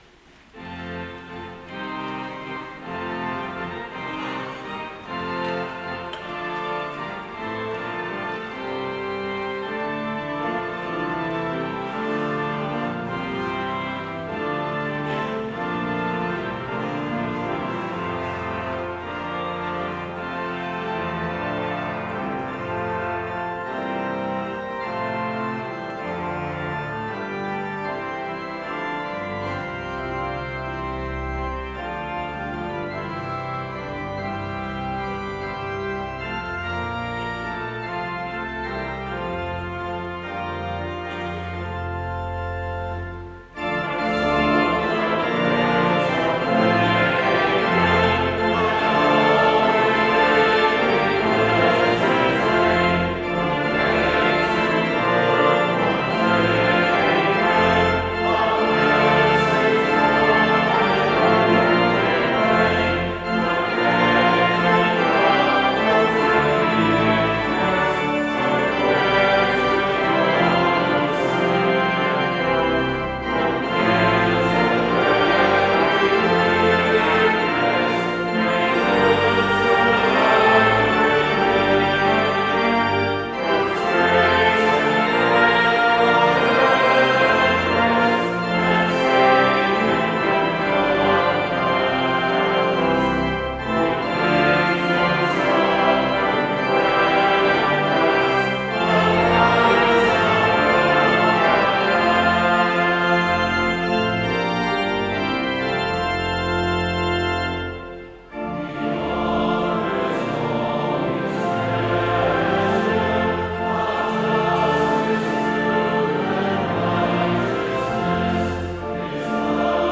11:00 service: St. John Adult Choir and Wall of Sound